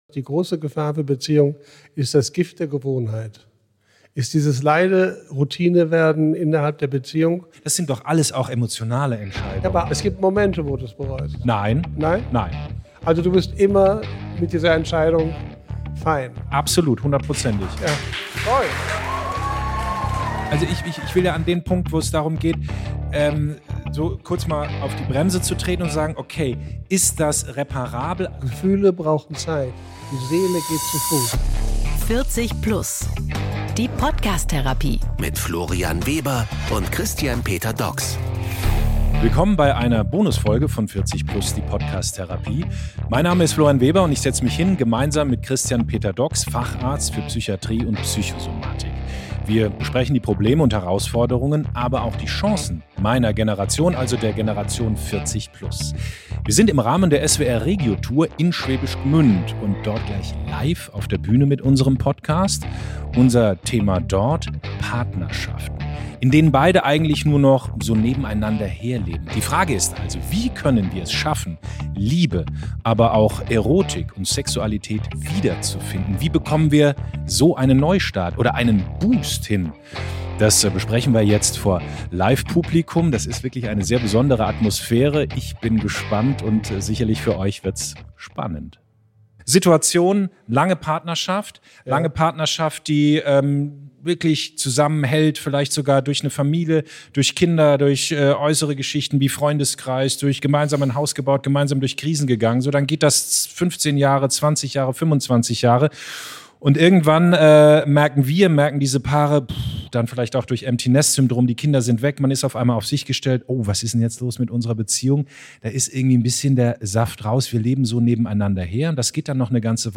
Das leise Gift der Gewohnheit - live aus Schwäbisch Gmünd ~ 40+ Die Podcast Therapie Podcast